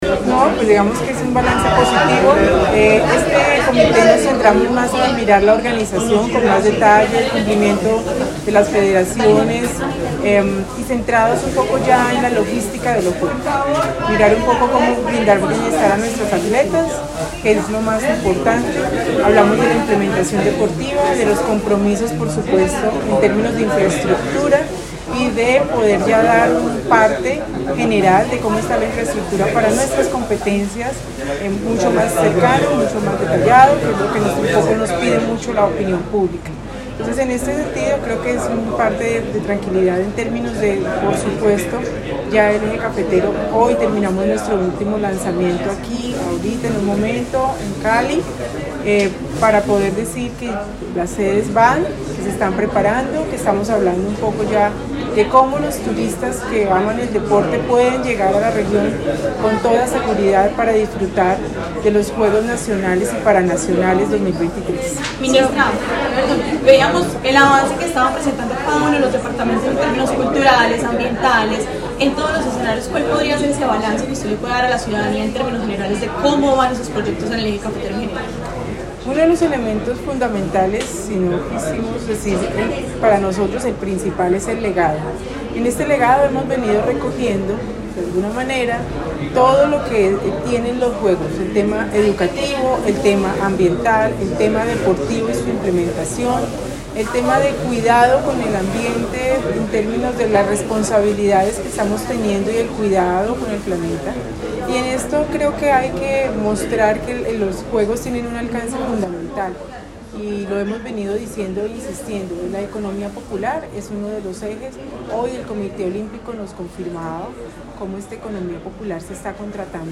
FULL_MINISTRA_DEL_DEPORTE_EN_COMITE_ORGANIZADOR_CALI.mp3